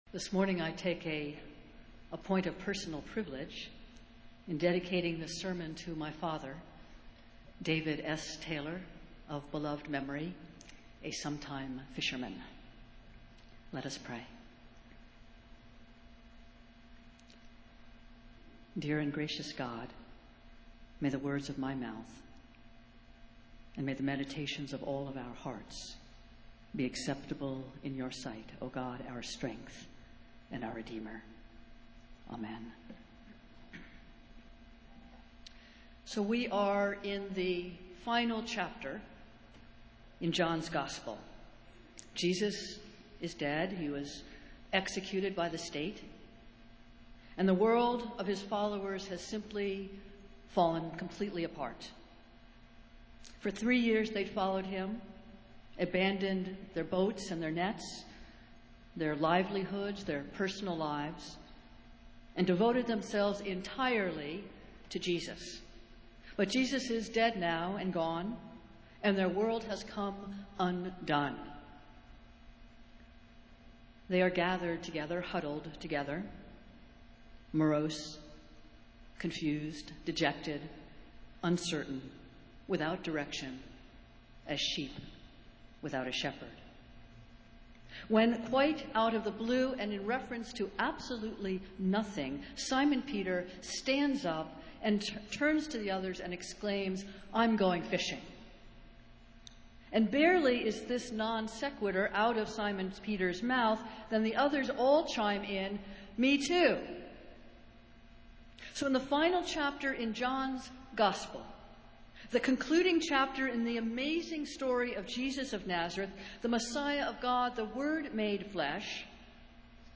Festival Worship - Ninth Sunday after Pentecost